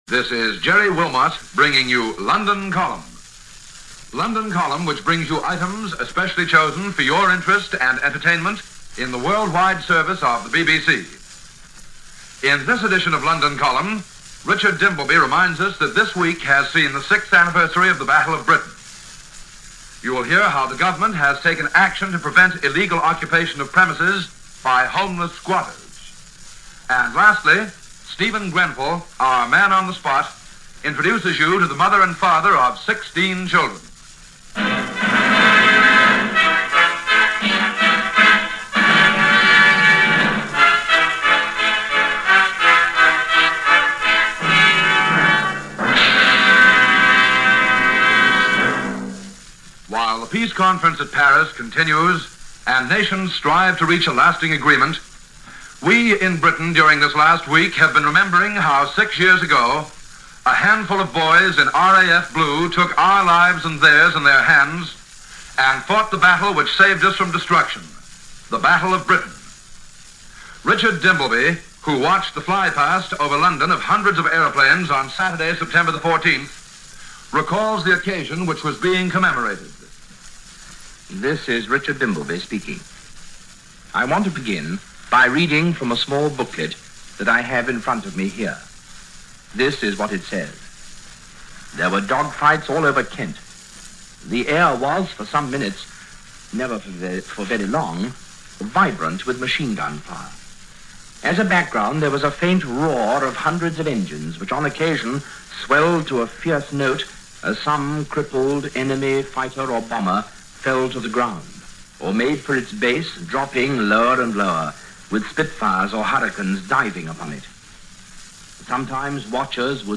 Postwar Britain, as it was portrayed by the BBC World Service on September 19, 1946 and the program London Column.